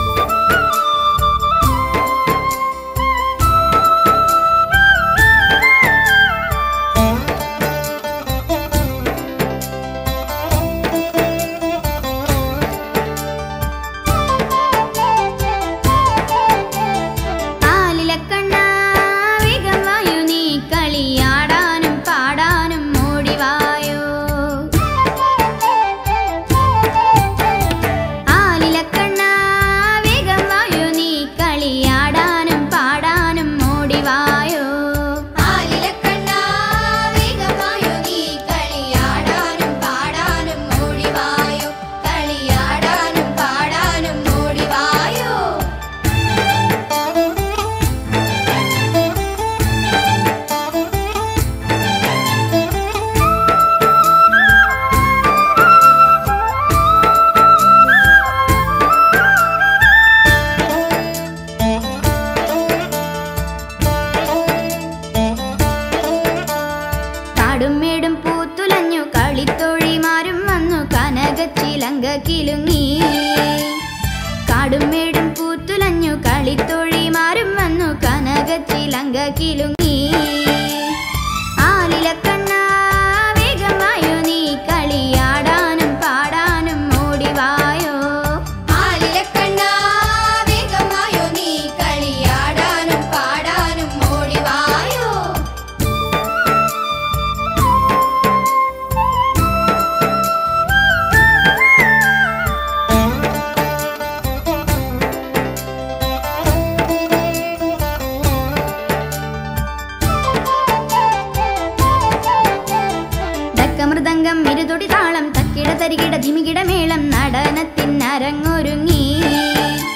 devotional song